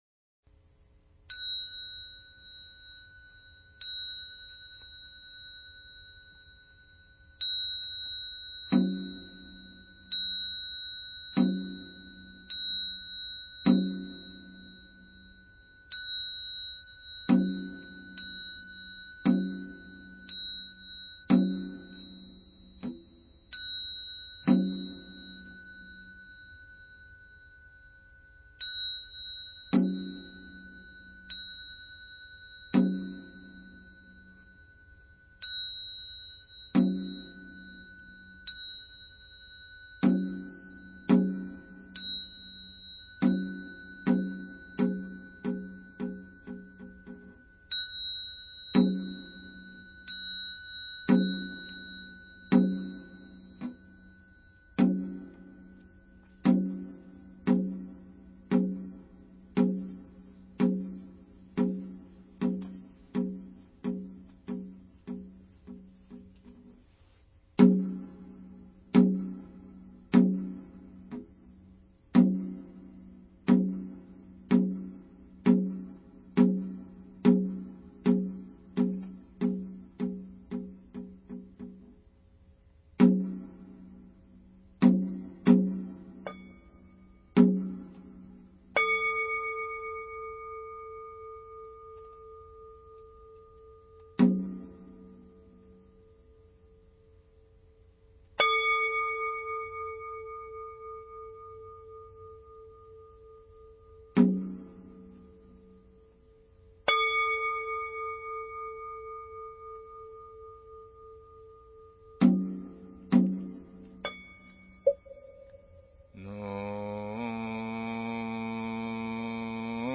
地藏经 诵经 地藏经--未知 点我： 标签: 佛音 诵经 佛教音乐 返回列表 上一篇： 香焚在上方 下一篇： 大般若波罗蜜多经第473卷 相关文章 萧中妙韵--佚名 萧中妙韵--佚名...